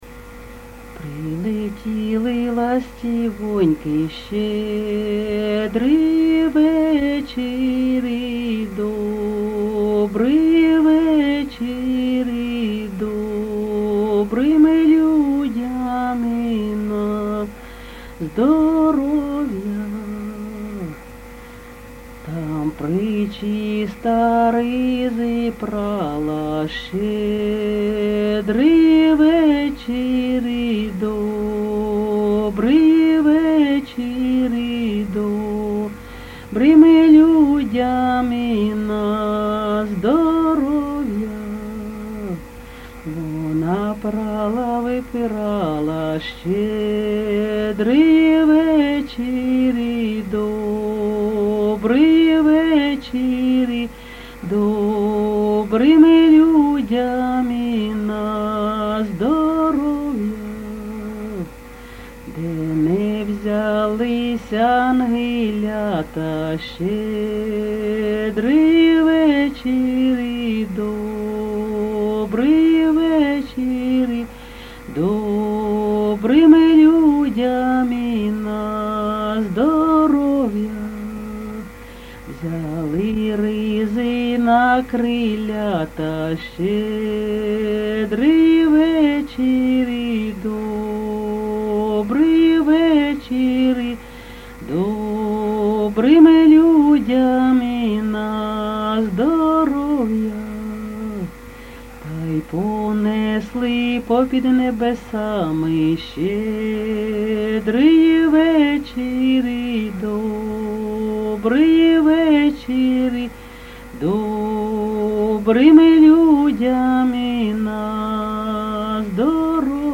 ЖанрЩедрівки
Місце записус. Серебрянка, Артемівський (Бахмутський) район, Донецька обл., Україна, Слобожанщина